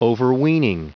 Prononciation du mot overweening en anglais (fichier audio)
Prononciation du mot : overweening